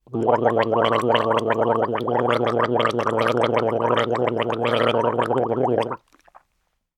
human
Human Gargle Male